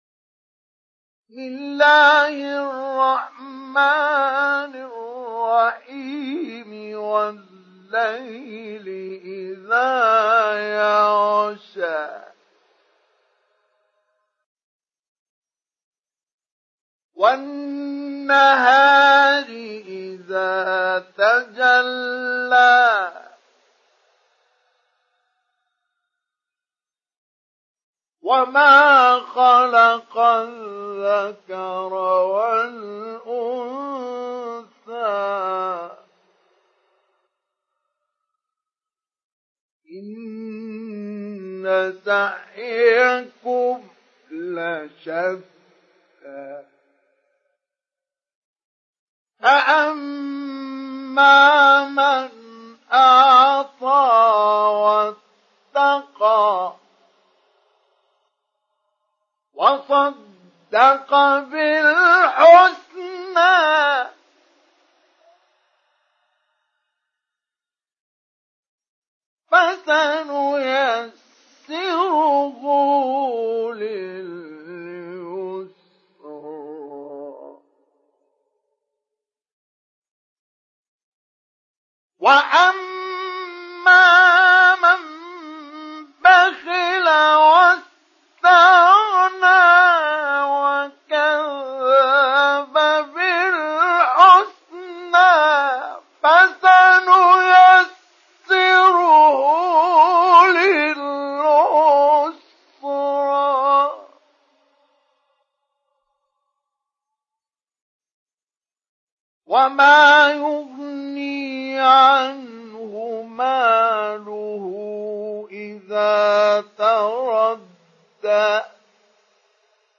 Download Surah Al Layl Mustafa Ismail Mujawwad